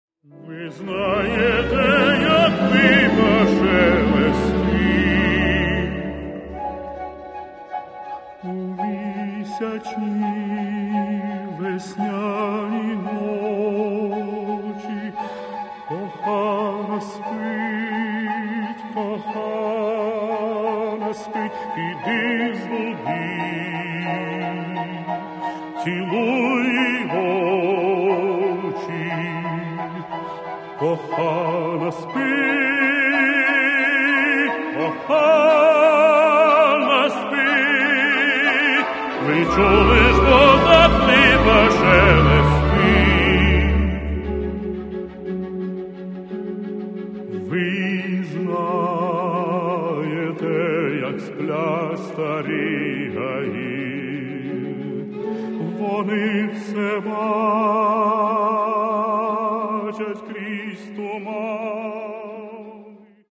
Каталог -> Класична -> Камерна
для баритону та камерного оркестру